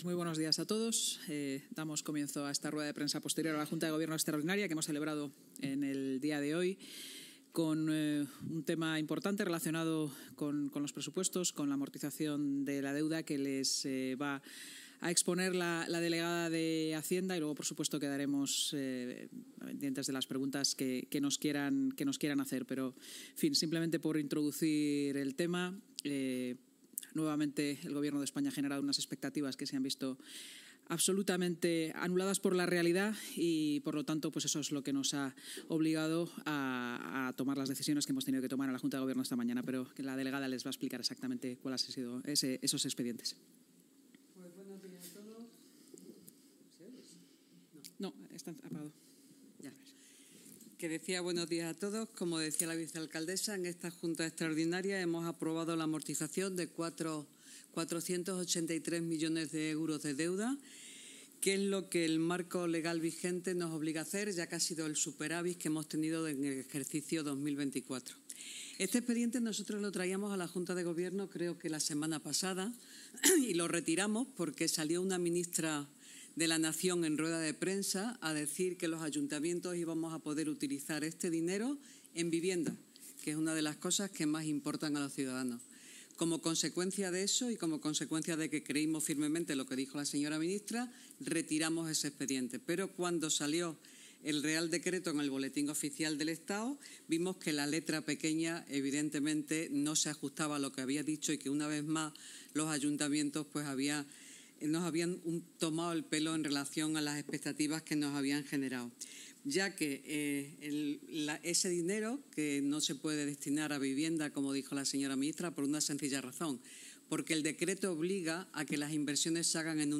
Esta amortización se ha visto condicionada por la decisión del Ministerio de Hacienda de aprobar el Real Decreto-ley 15/2025 este jueves, de manera que, hasta ahora, ha generado falsas expectativas sobre las posibilidades que las entidades locales tienen de realizar inversiones en vivienda o en proyectos transformadores de inversión al destinar el superávit obtenido en 2024 a otros proyectos que no sean la amortización anticipada de deuda, tal y como ha destacado la vicealcaldesa de Madrid y portavoz municipal, Inma Sanz, en la rueda de prensa posterior a la Junta de Gobierno.